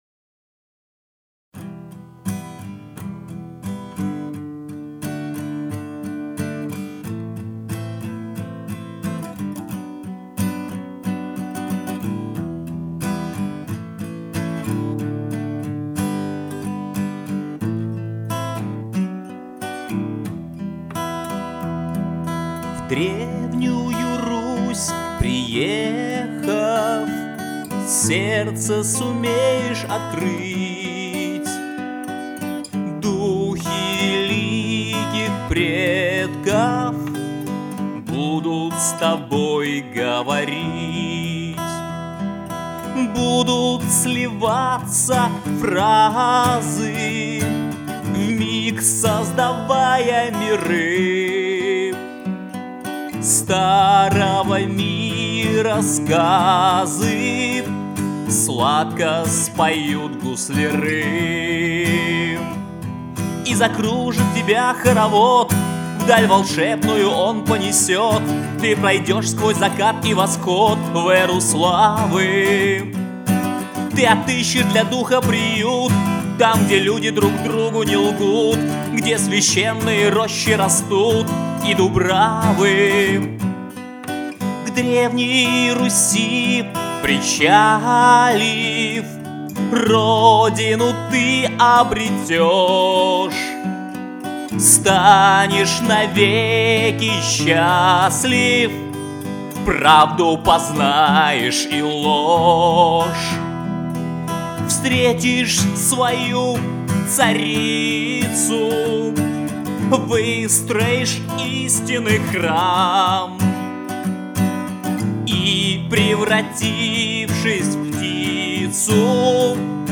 Акустический вариант